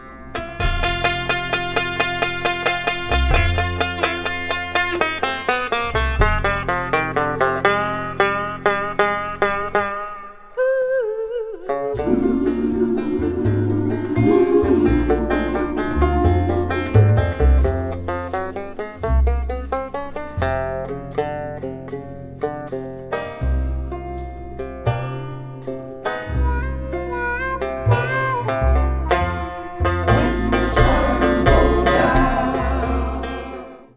voice, banjo
harmonica
bass
percussion